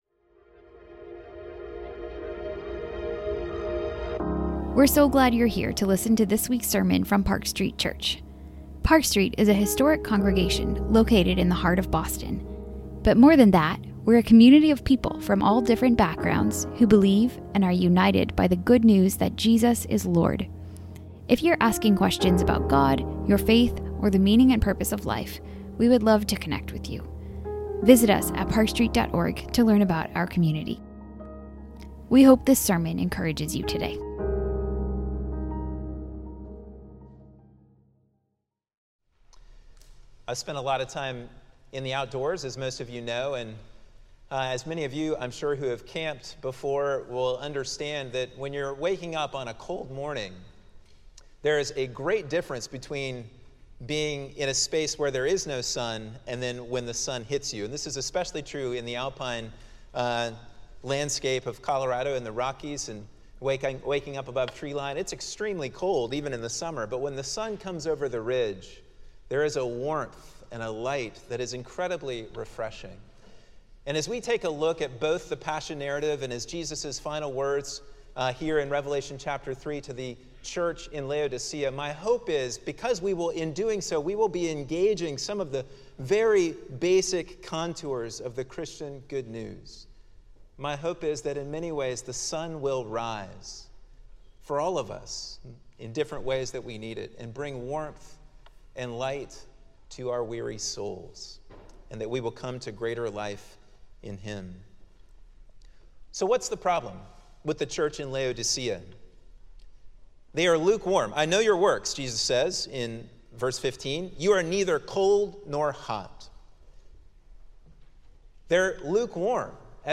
Sermons - Park Street Church